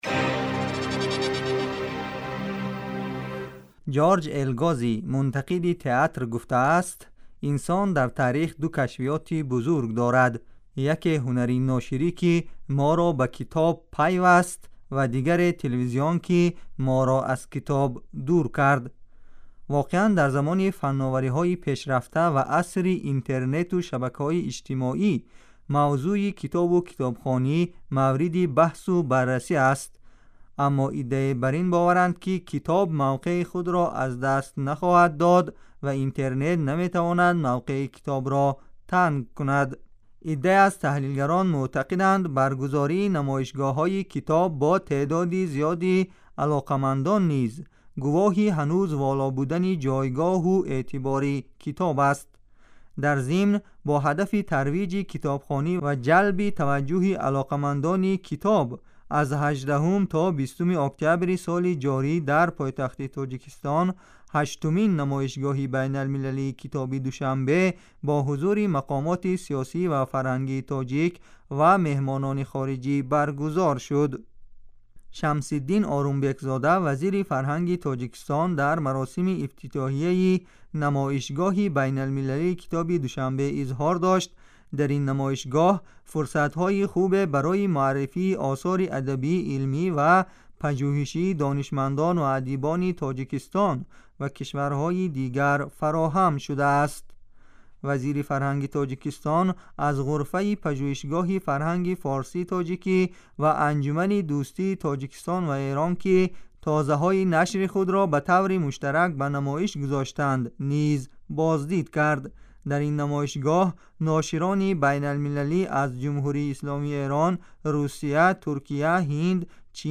гузорише вижа